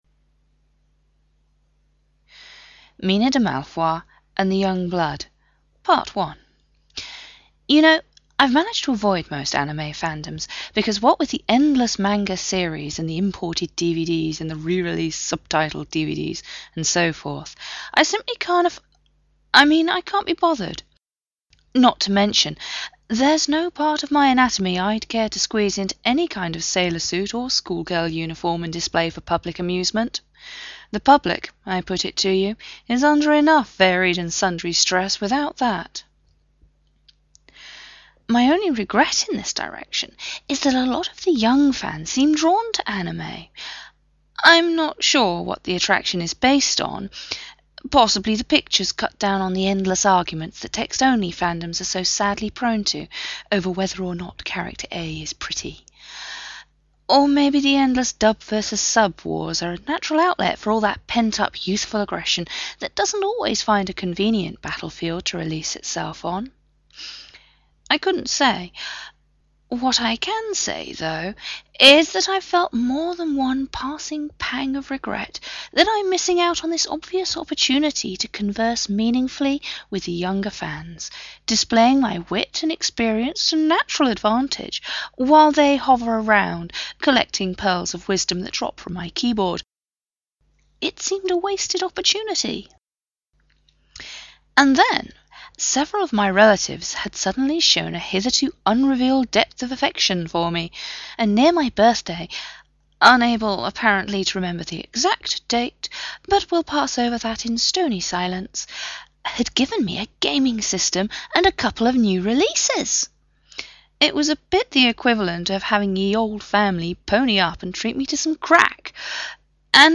Podfic! Mina de Malfois and the Young Blood